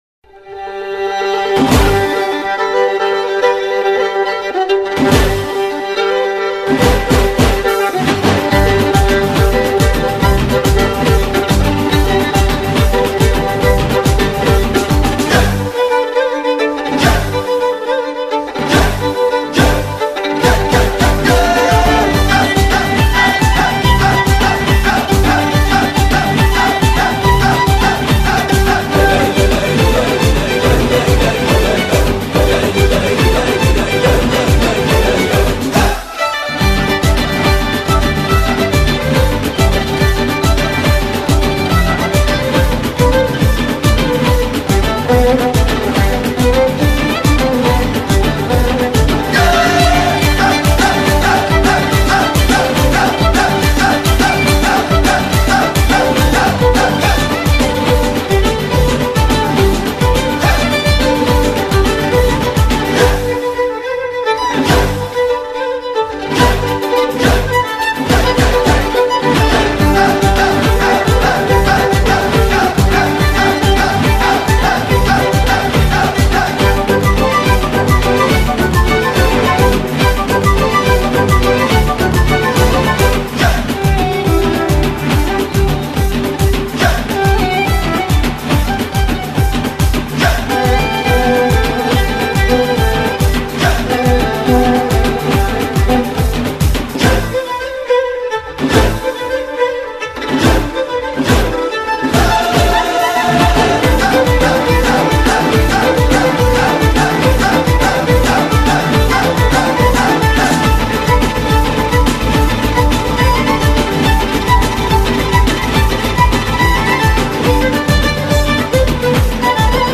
آهنگ شاد بیکلام ویولن: